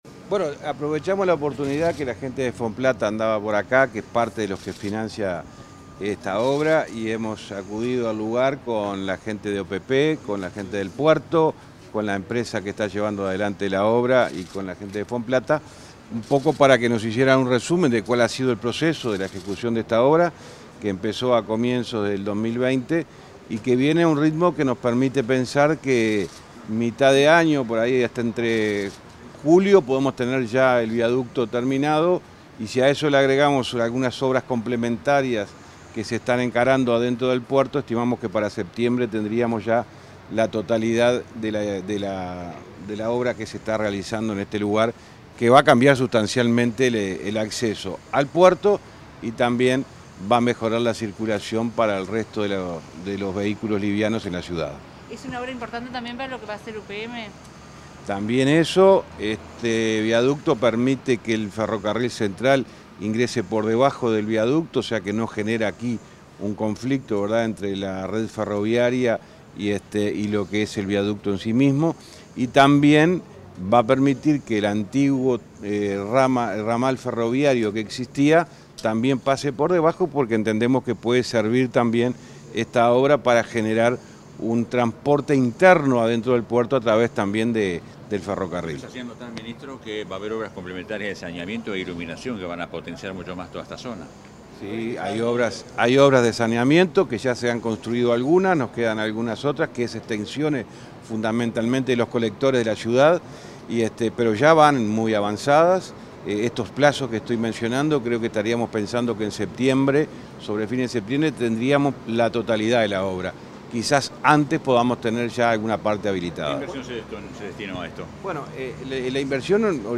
Declaraciones a la prensa del ministro de Transporte y Obras Públicas, José Luis Falero
Tras el recorrido, efectuó declaraciones a la prensa.